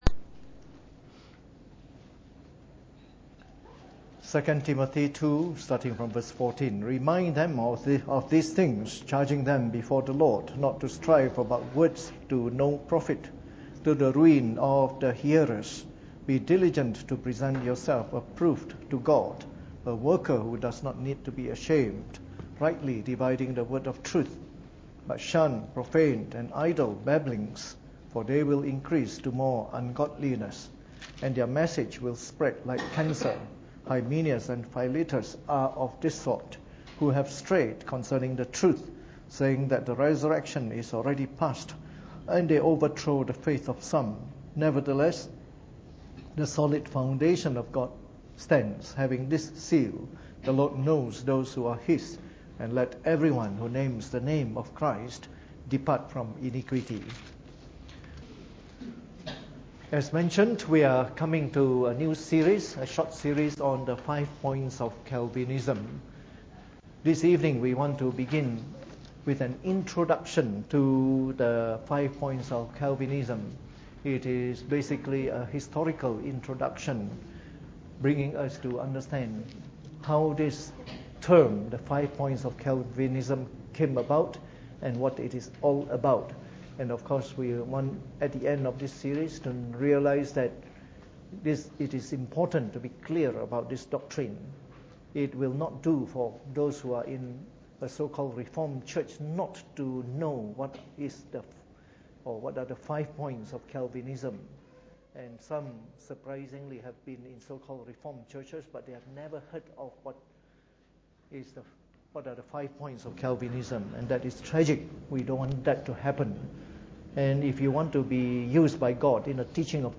Preached on the 3rd of August 2016 during the Bible Study, from our new series on the Five Points of Calvinism.